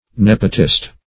Nepotist \Nep"o*tist\, n.